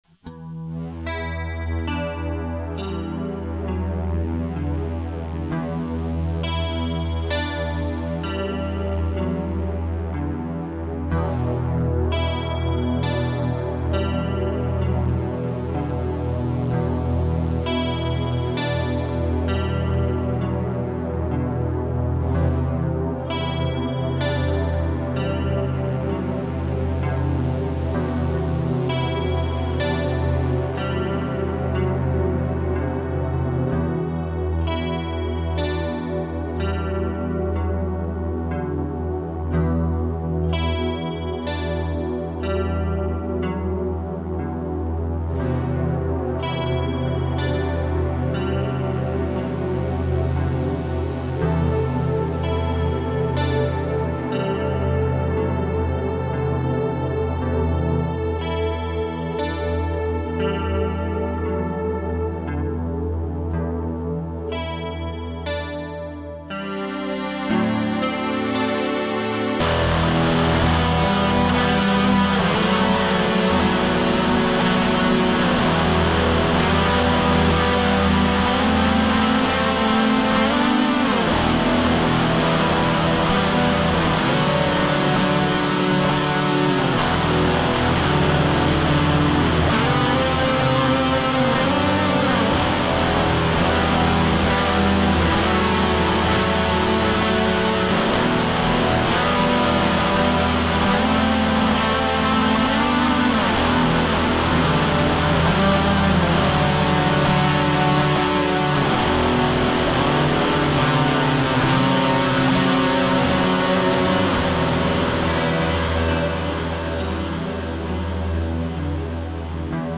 Depresyjne klimany ?